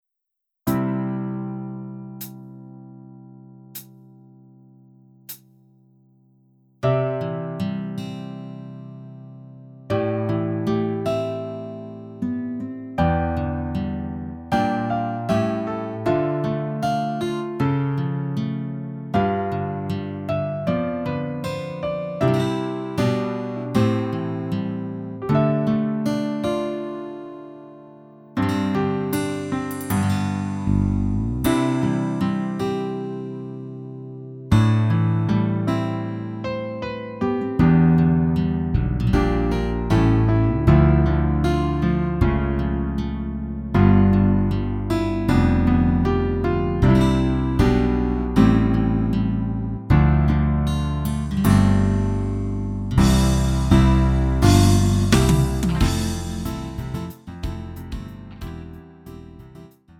음정 -1키 3:45
장르 구분 Lite MR